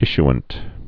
(ĭsh-ənt)